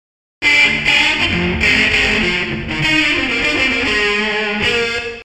（キー F /テンポ 100 に変更）
4. Comp → AmpSim → Reverb → Chorus
リバーブは、プリセットのままなので非現実的なくらい濃い目ですね。